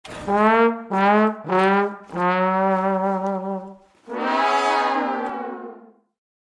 На этой странице собраны звуки грустного тромбона (sad trombone) — узнаваемые меланхоличные мотивы, часто используемые в кино и юмористических роликах.
Тромбон с эффектом фейла